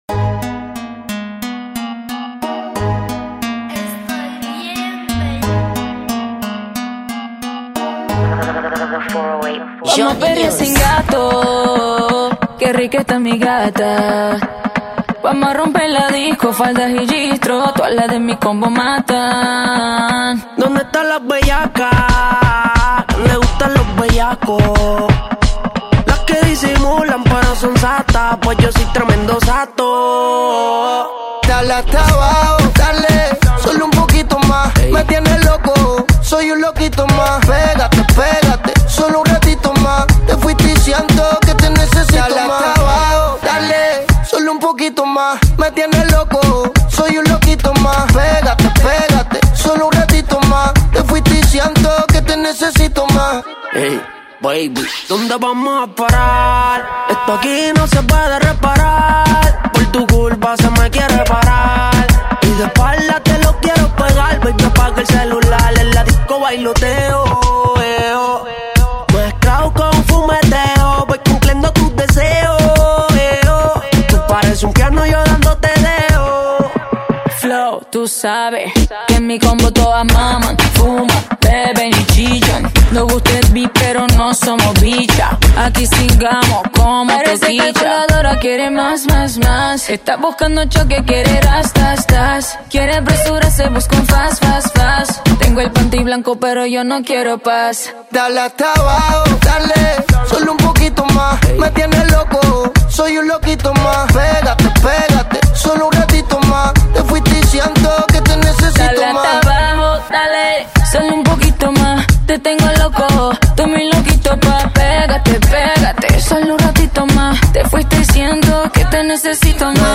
Gênero: Reggeaton